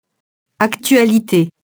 actualité [aktɥalite]